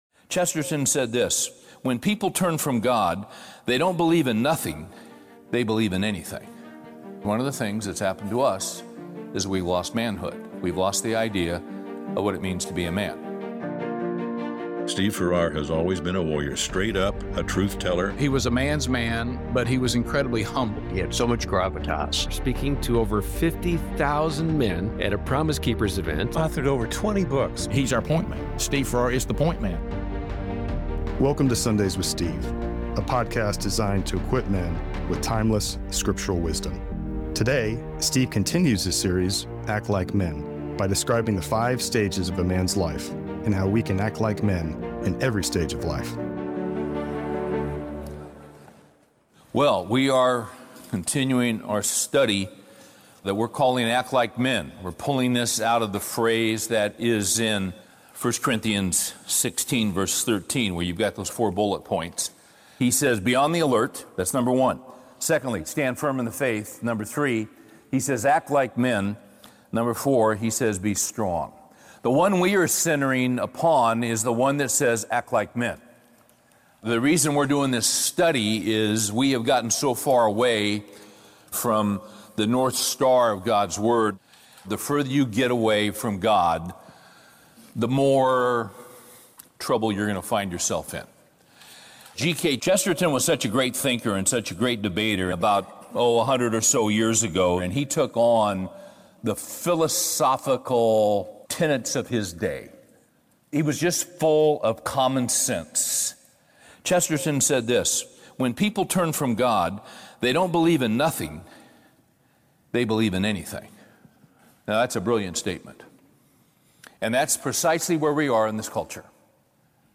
A message from the series "Courage In Chaos." Series: Courage in Chaos, 11-10-20 Title: Three Certainties in an Uncertain Election Scriptures: Daniel 2, Psalm 37